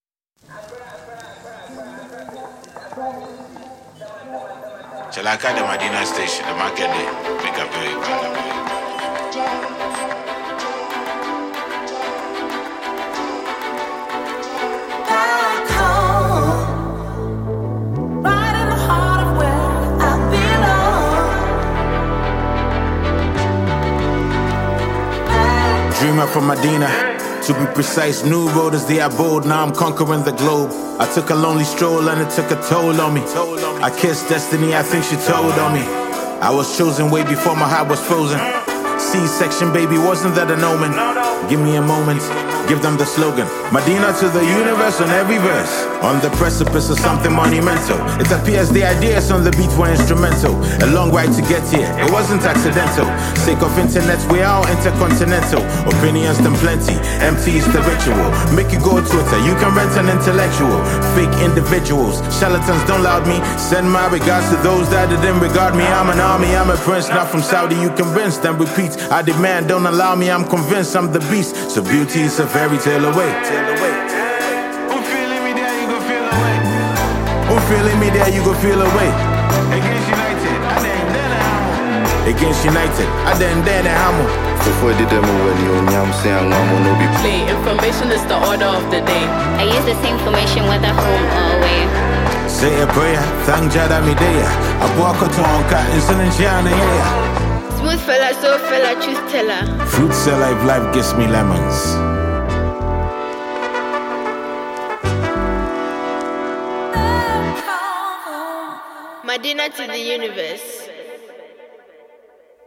Ghanaian versatile rapper